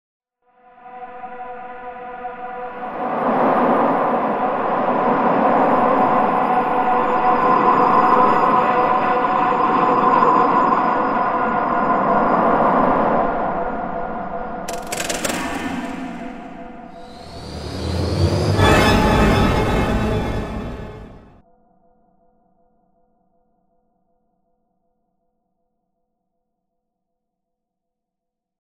Sound Effect Horror Intro 7.mp3